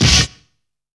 CRASH STAB.wav